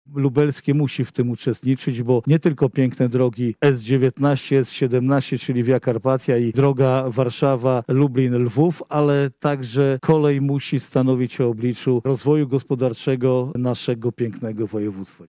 -mówi Marszałek Województwa Lubelskiego Jarosław Stawiarski.